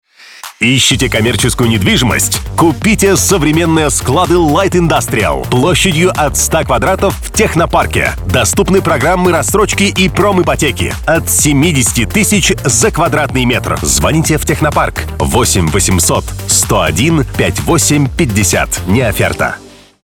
Радиоролик-победитель, который транслировался на радиостанции «Бизнес FM», доступен для прослушивания по